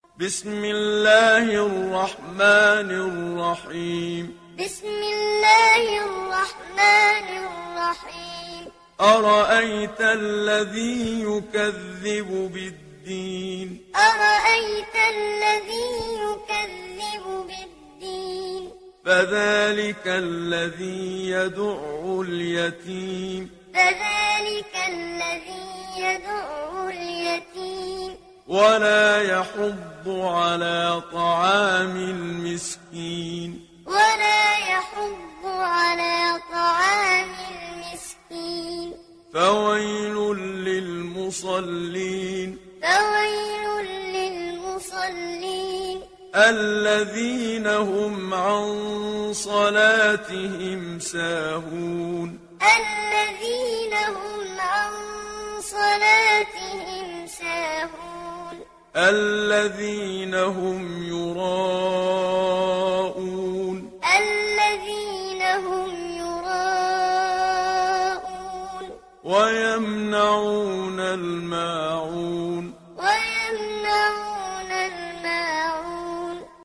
সূরা আল-মা‘ঊন mp3 ডাউনলোড Muhammad Siddiq Minshawi Muallim (উপন্যাস Hafs)